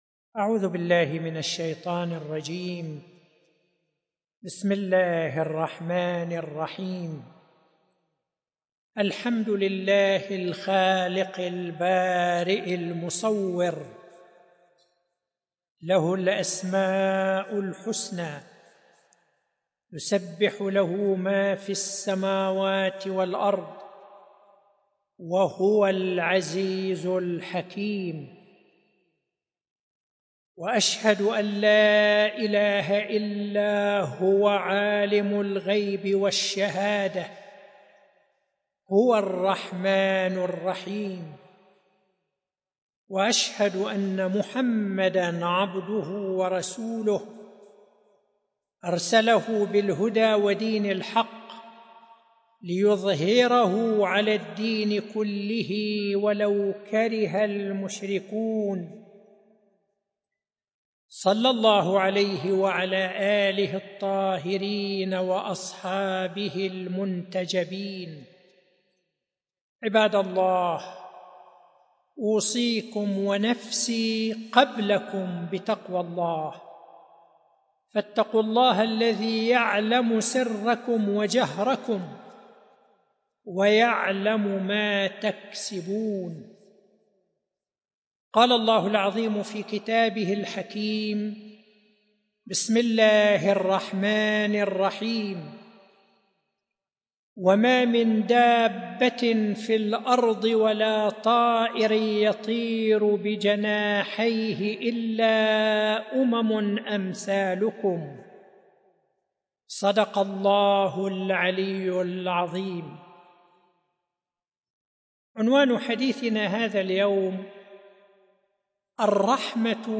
ملف صوتی الرحمة والرفق بالحيوان بصوت الشيخ حسن الصفار